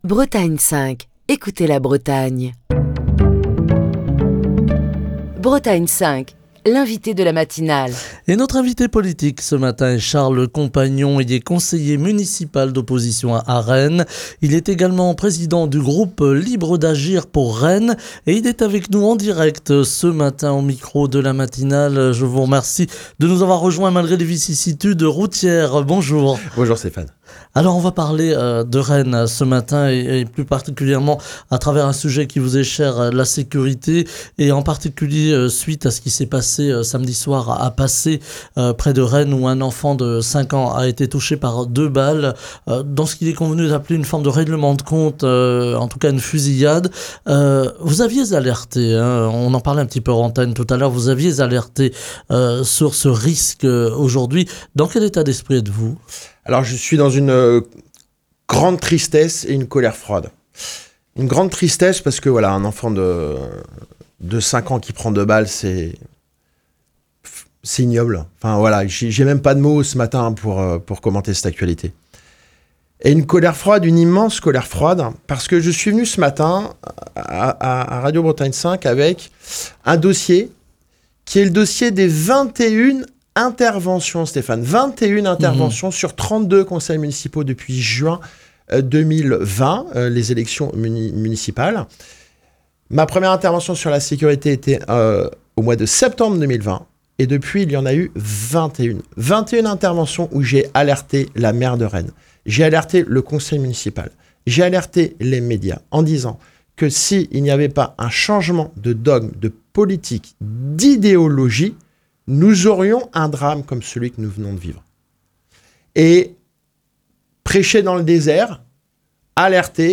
Ce mardi, Charles Compagnon, conseiller municipal d'opposition à Rennes (Horizons), président du groupe Libres d'Agir pour Rennes est l'invité politique de la matinale de Bretagne 5, pour évoquer les problèmes de sécurité liés principalement au trafic de drogue dans la capitale bretonne.